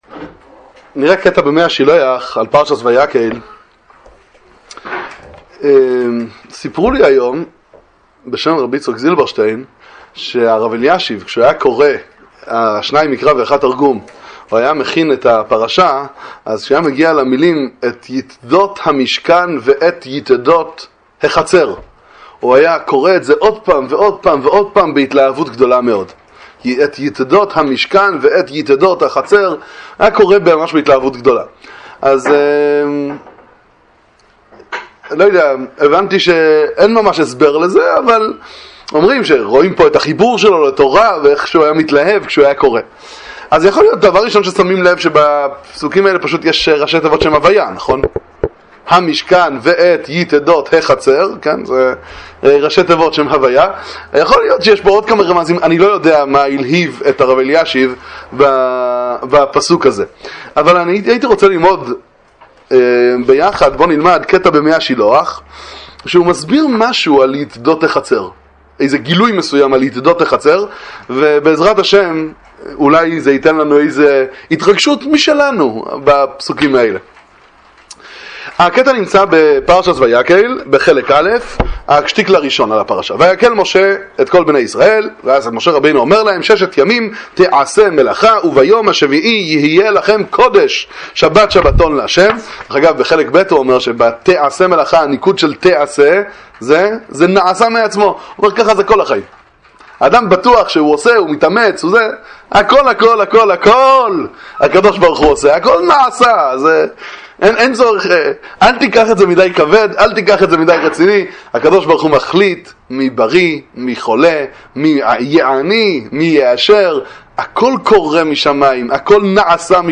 דבר תורה קצר לפרשת השבוע ויקהל פקודי מספר מי השילוח איזביצה